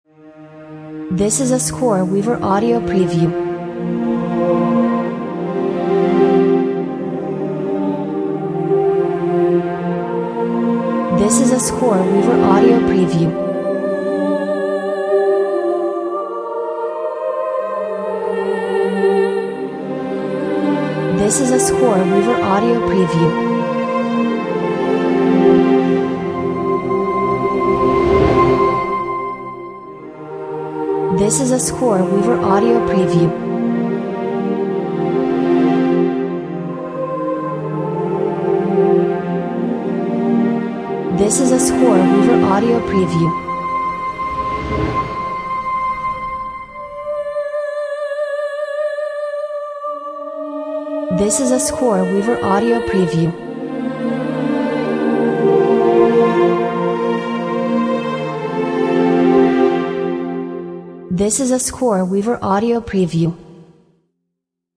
Conveys a sense of wonder and awe.